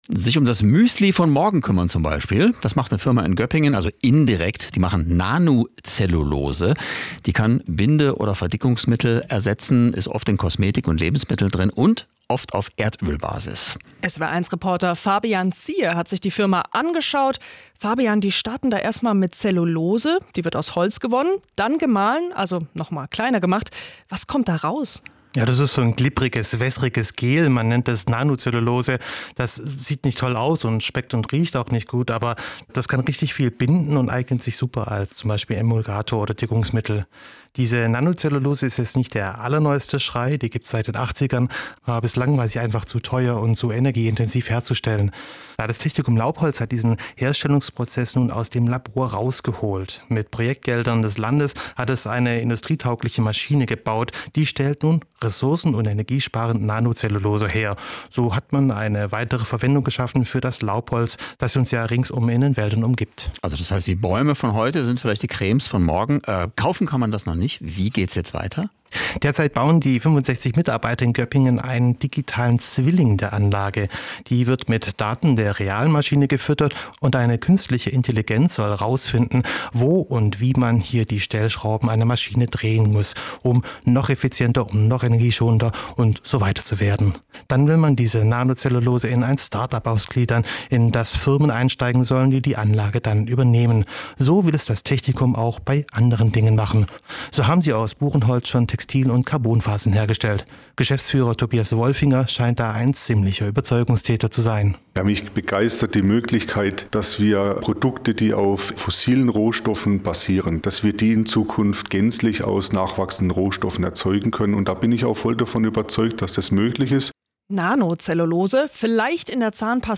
SWR1 Talk
Talk-Nanocellulose-SWR1.wav